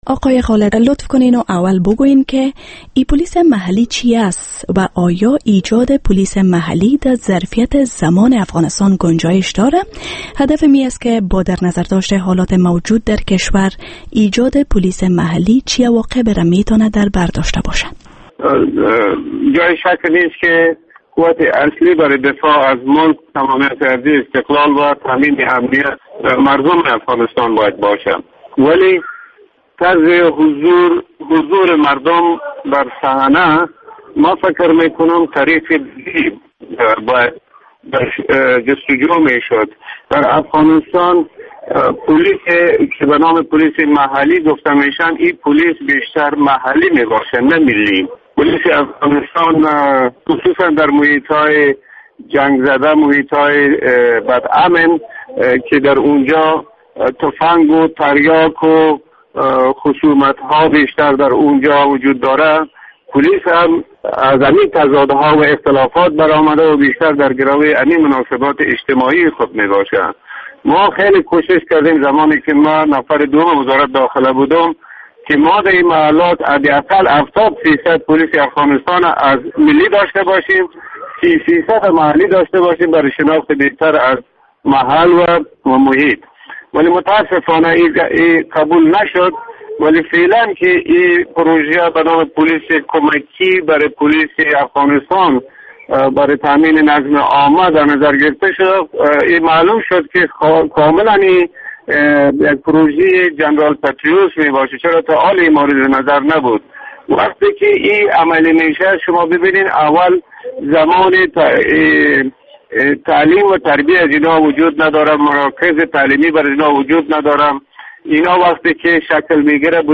مصاحبه با جنرال هادی خالد در مورد عواقب موجودیت دو نوع پولیس در افغانستان